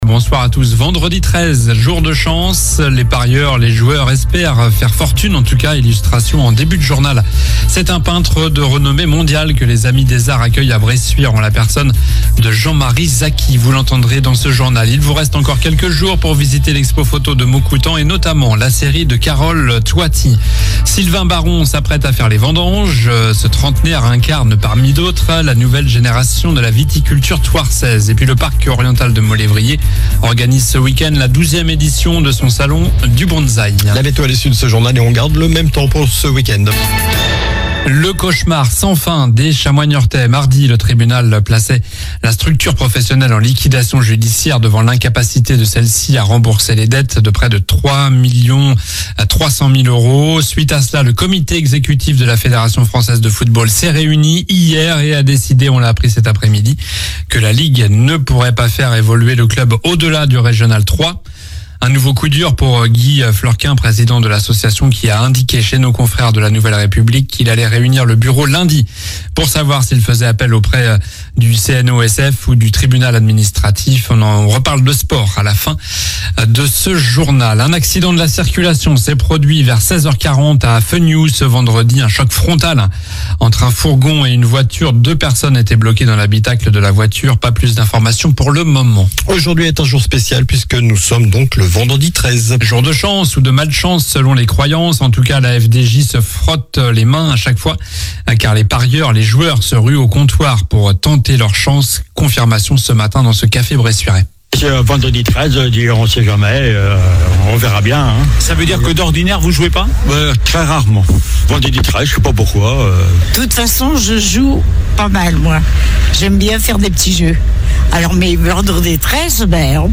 Journal du vendredi 13 septembre (soir)